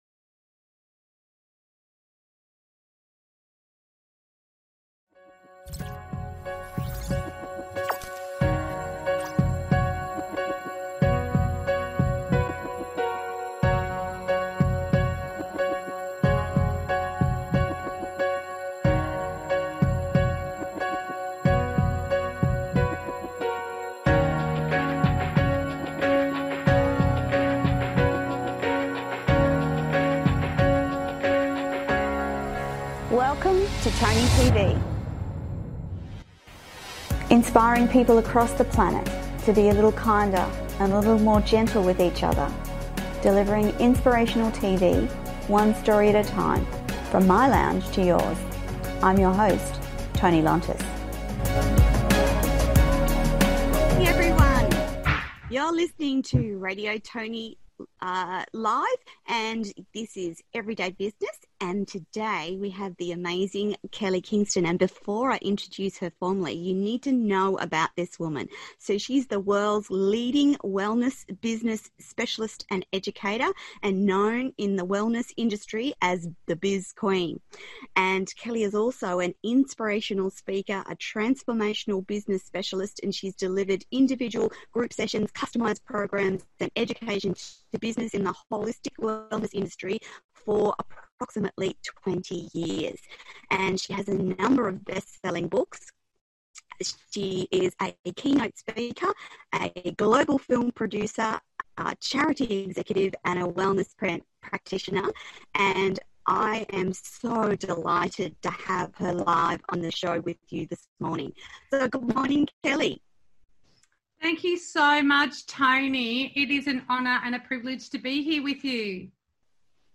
(( NOTE: The audio hiccups a lot - and same with the video - something we couldn't prevent ))